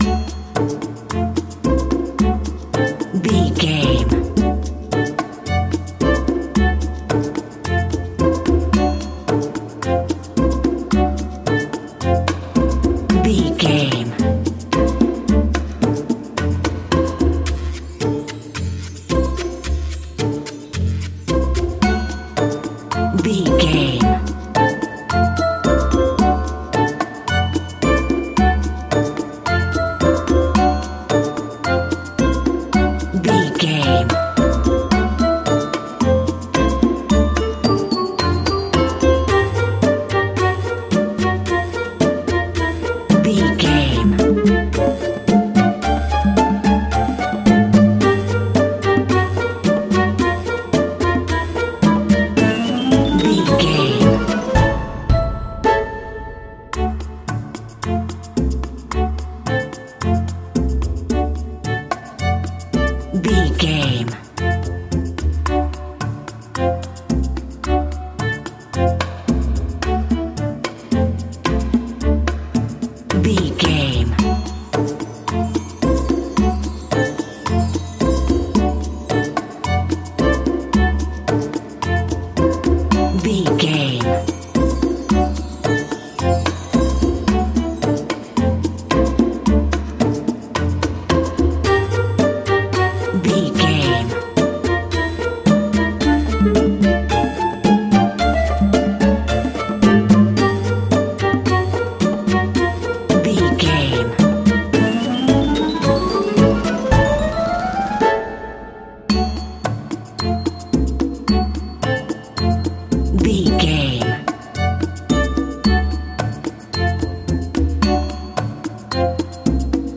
Ionian/Major
orchestra
flutes
percussion
conga
oboe
strings
silly
circus
goofy
comical
cheerful
perky
Light hearted
quirky